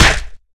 bigLogHit.wav